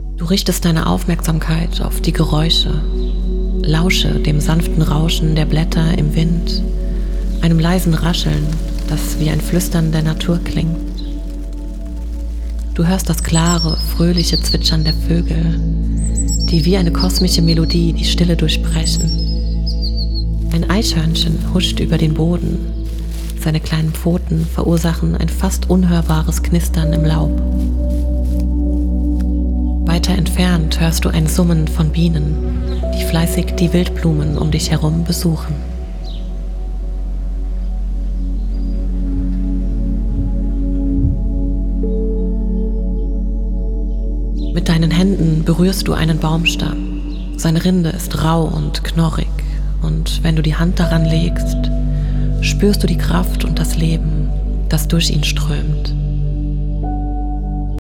Frequenz: 639 Hz – Fördert Fantasie und innere Ausgeglichenheit.
8D-Musik: Lässt Naturgeräusche und Trommeln lebendig werden und stärkt Kreativität.